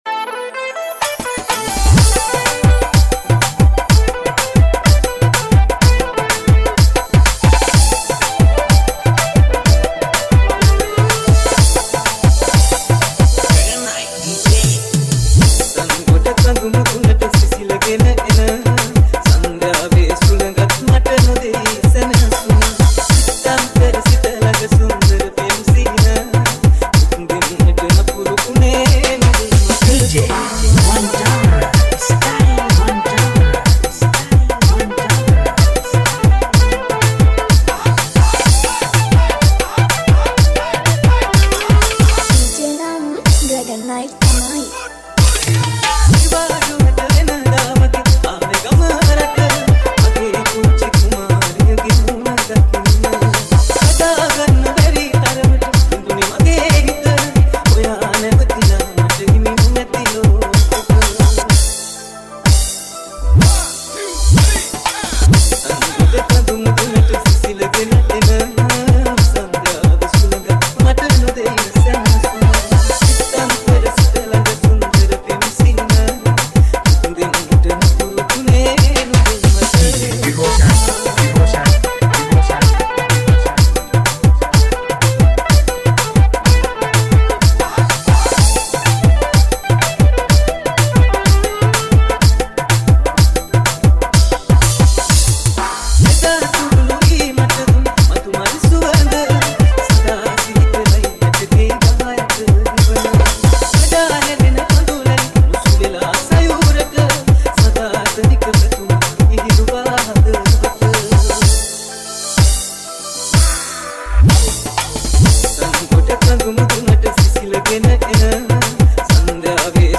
Genre - 6-8